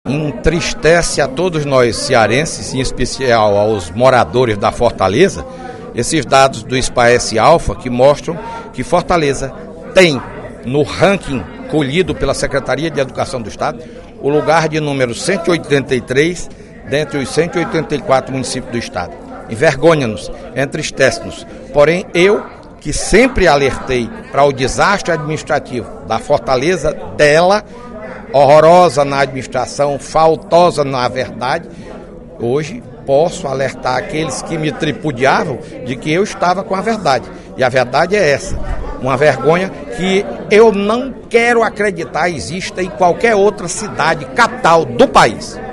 O deputado Fernando Hugo (PSDB) criticou, durante pronunciamento na sessão plenária desta terça-feira (22/05), a Prefeitura de Fortaleza pela condução de políticas educacionais e pelos resultados ruins obtidos no quinto ciclo de avaliação da alfabetização, através do Sistema Permanente de Avaliação da Educação Básica (Spaece), em sua vertente Spaece-Alfa.